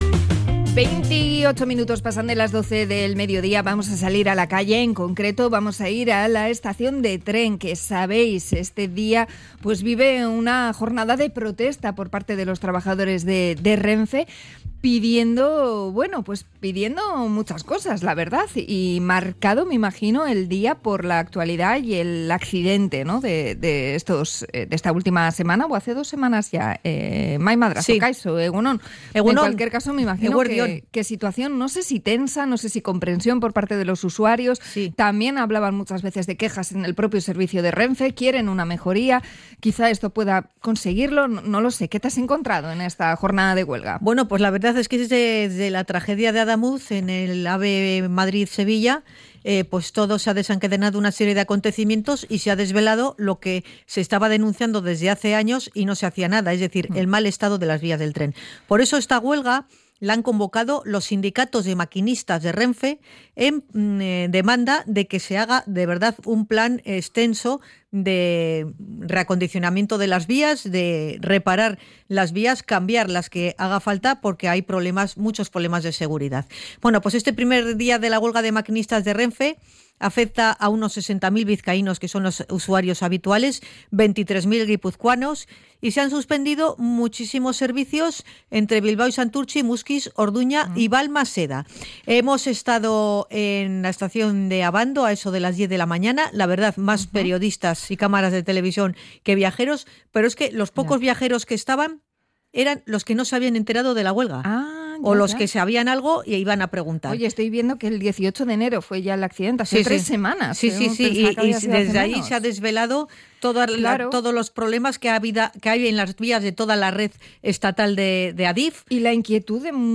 Hablamos con viajeros que no se han enterado de la huelga de maquinistas de RENFE
HUELGA-RENFE-REPORTAJE.mp3